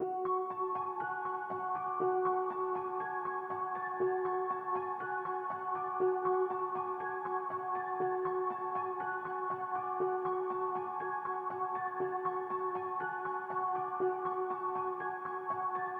描述：用Nexus制造120BPM调F调